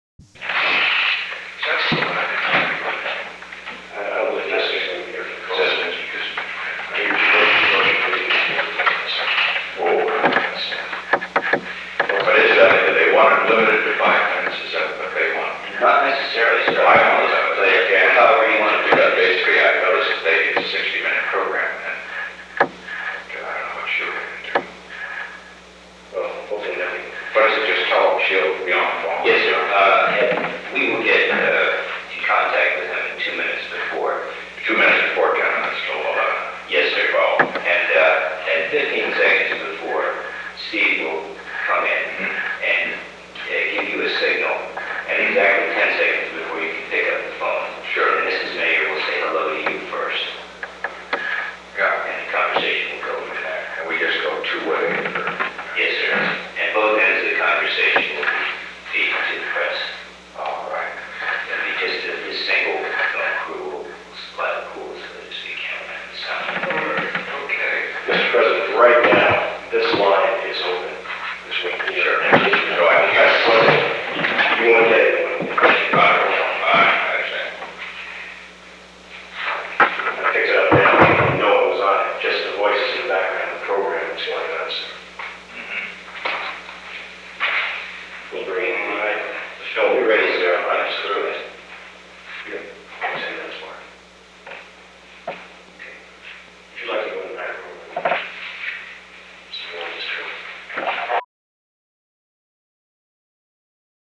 The Oval Office taping system captured this recording, which is known as Conversation 754-003 of the White House Tapes.
Location: Oval Office
Telephone call to Golda Meir
[Photograph session]